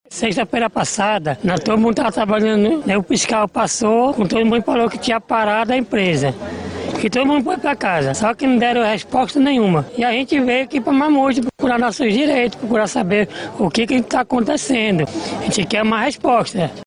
SONORA01-MANIFESTACAO-GARI-.mp3